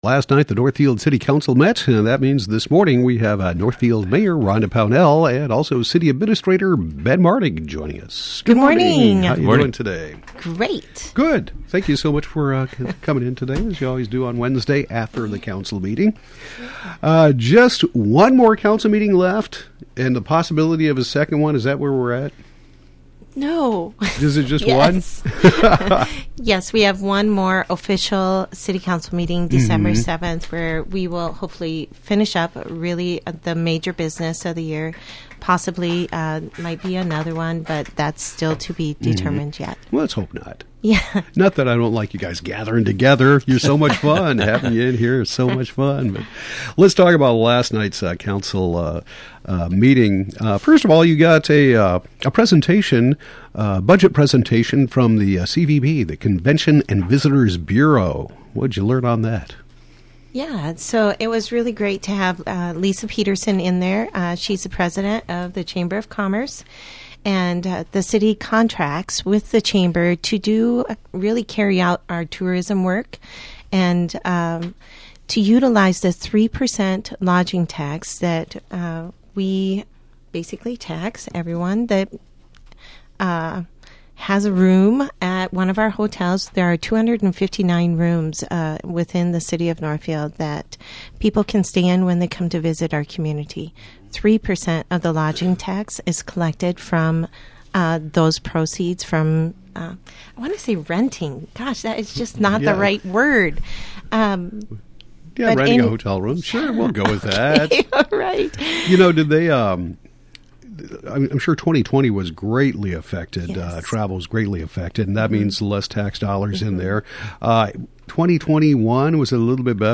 Northfield Mayor Rhonda Pownell and City Administrator Ben Martig discuss the November 16 City Council meeting.